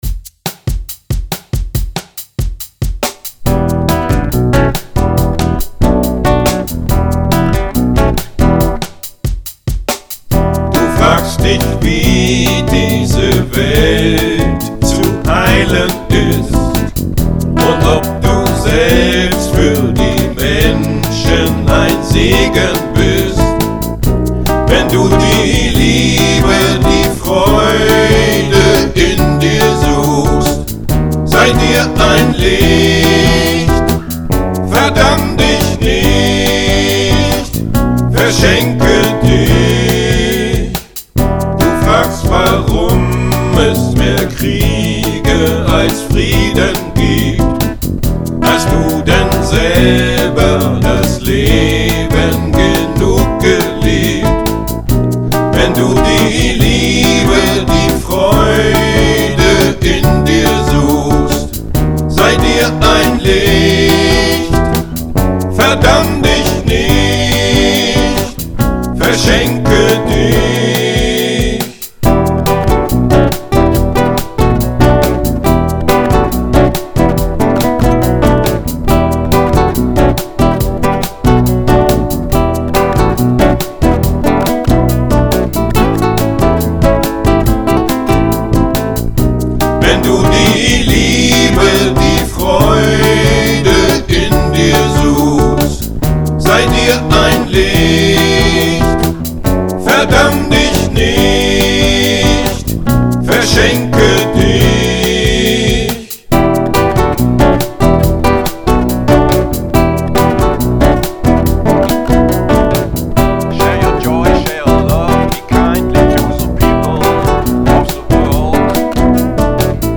Die Audio-Tracks sind mit allen Instrumenten (und Vocals) von mir allein eingespielt worden (home-recording).
Seit Frühjahr 2012 benutze eine etwas bessere Aufnahmetechnik (mit einem externen Audio-Interface und einem besseren Mikrofon).